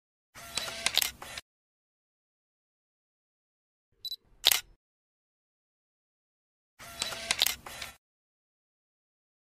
Tiếng Bấm camera, Lấy nét, chụp ảnh…
Thể loại: Tiếng đồ công nghệ
Description: Tiếng bấm camera, âm thanh lấy nét và chụp ảnh, âm thanh này bao gồm tiếng "click" khi nhấn nút chụp, tiếng "tạch" hoặc "bíp" báo hiệu quá trình lấy nét, âm thanh shutter, tiếng chụp hình, âm thanh màn trập, tiếng bấm máy, hiệu ứng chụp ảnh, âm click camera, sound effect camera....
tieng-bam-camera-lay-net-chup-anh-www_tiengdong_com.mp3